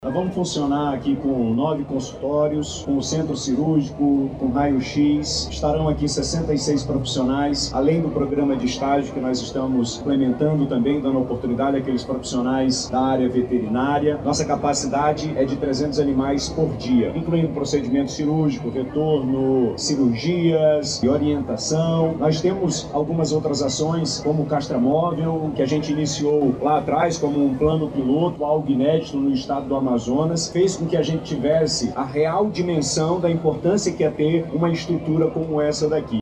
Em seguida, o Governador Wilson Lima ressaltou os serviços que serão ofertados no local.